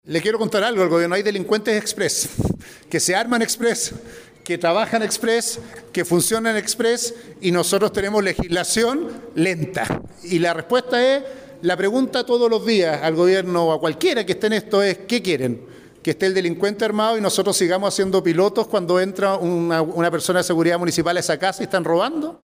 La autoridad ñuñoína sostuvo que debemos debatir con base en realidades, teniendo sentido de urgencia en temas tan importantes para la ciudadanía.